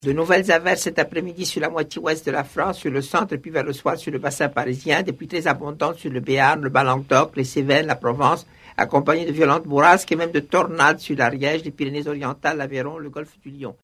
Avoir déjà vu neiger sous entend en effet d’avoir connu plus d’un rude hiver, de ceux qui ont vu rôder la bête du Gévaudan sous les remparts de la ville (ou a minima de ceux qui ont permis de croiser l’homme qui a vu l’homme qui a vu l’ours) ou qui faisaient s’envoler la voix chevrotante d’Albert Simon (écouter ci-dessous), grand maître du temps qu’il allait peut-être faire le lendemain des années surannées¹.
¹Sur Europe n°1.